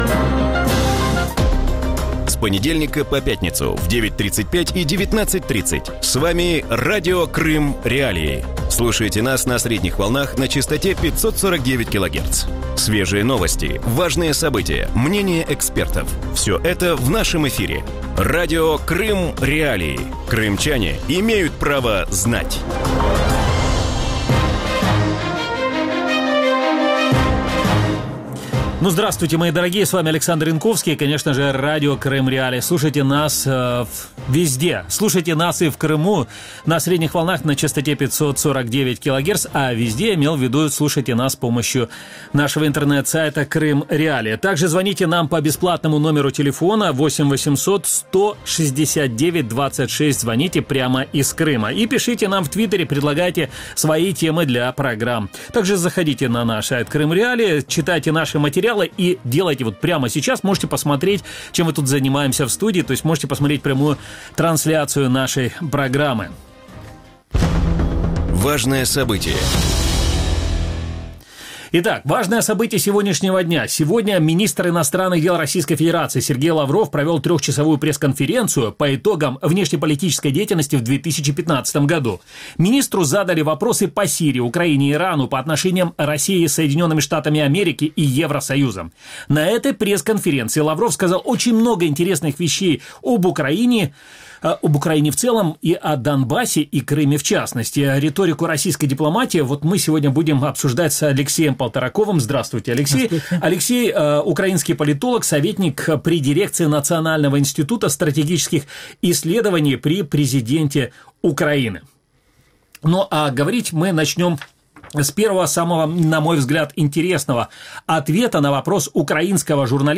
В вечернем эфире Радио Крым.Реалии обсуждают итоговую пресс-конференцию министра иностранных дел Российской Федерации Сергея Лаврова.